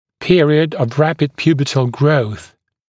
[‘pɪərɪəd əv ‘ræpɪd ‘pjuːbətl grəuθ][‘пиэриэд ов ‘рэпид ‘пйу:бэтл гроус]период быстрого пубертатного роста